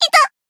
贡献 ） 分类:蔚蓝档案语音 协议:Copyright 您不可以覆盖此文件。
BA_V_Mutsuki_Battle_Damage_2.ogg